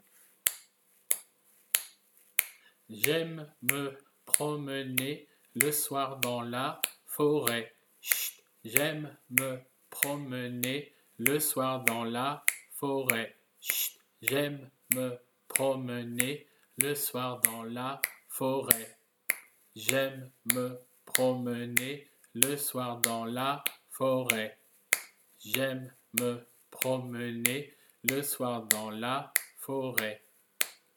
“J’aime me promener”, apprentissage des phrases parlées :